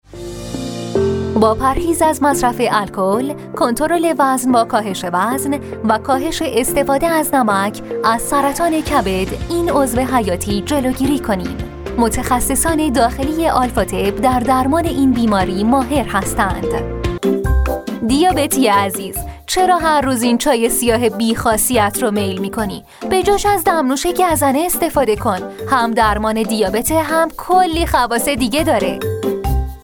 Female
Young
Adult
Commercial
CommercialR-resturant